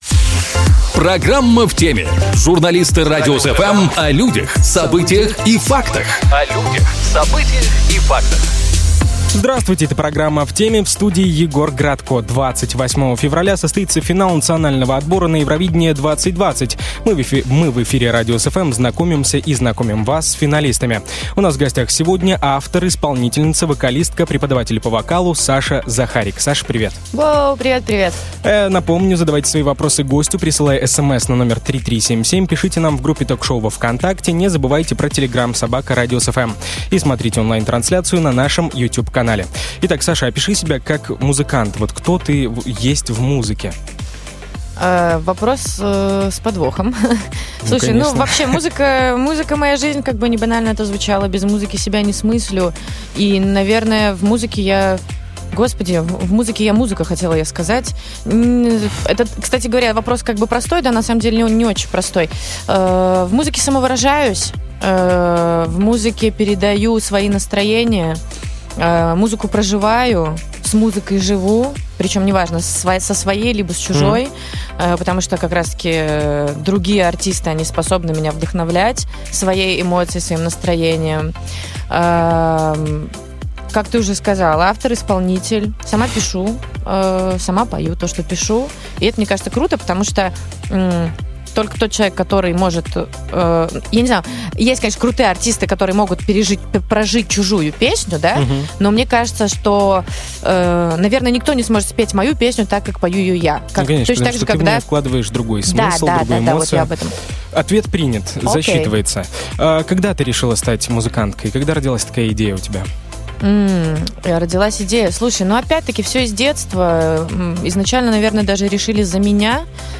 Мы в эфире "Радиус FМ" знакомимся с финалистами.